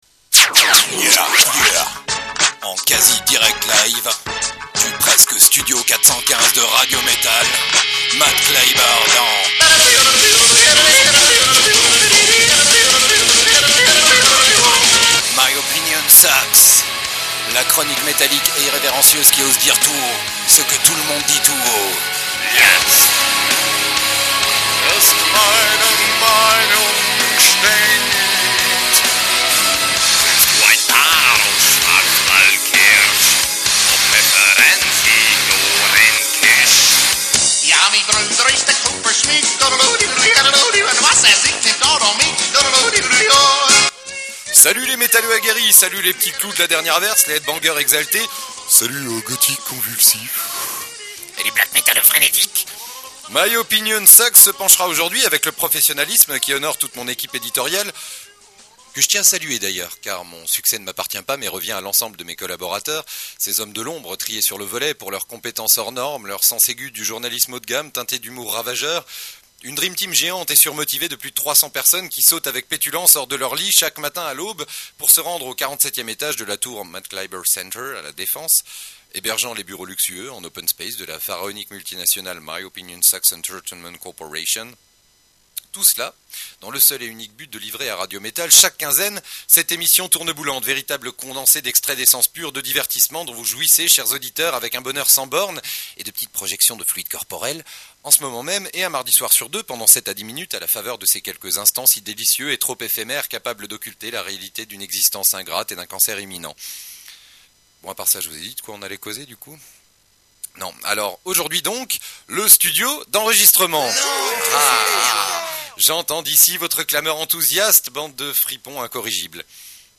(Désolé encore pour la qualité du son) My Opinion Sucks 03 – Le studio